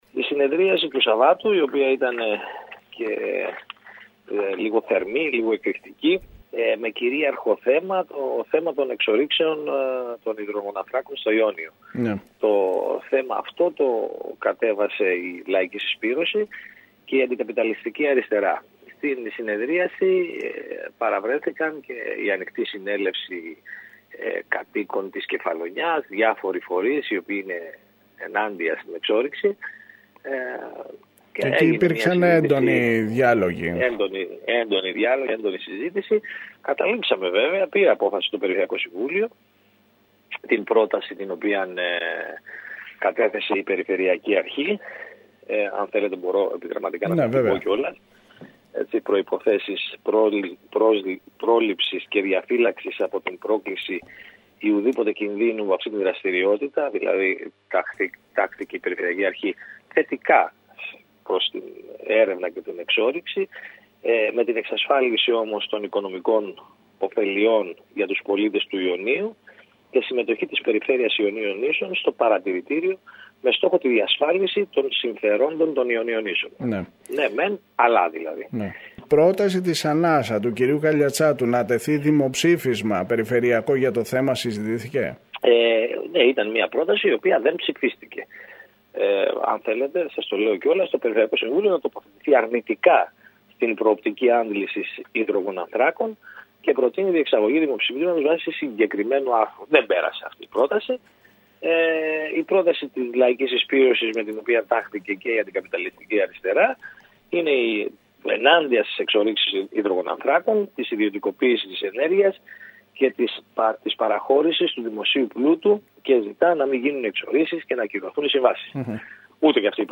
Στην ειδική συνεδρίαση της Κυριακής συζητήθηκε ο προϋπολογισμός και το ειδικό σχέδιο δράσης της Περιφέρειας Ιονίων Νήσων. Ακούμε τον πρόεδρο του σώματος, Νίκο Μουζακίτη.